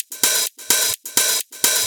Index of /VEE/VEE Electro Loops 128 BPM
VEE Electro Loop 184.wav